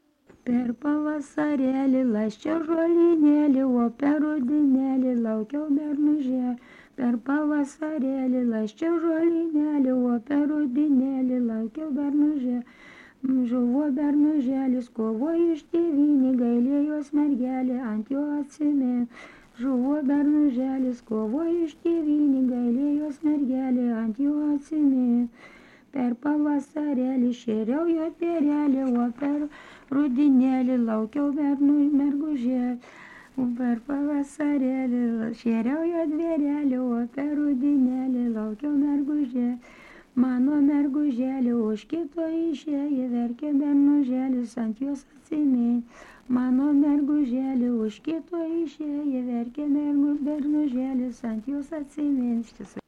Subject daina
Erdvinė aprėptis Tūbinės I
Atlikimo pubūdis vokalinis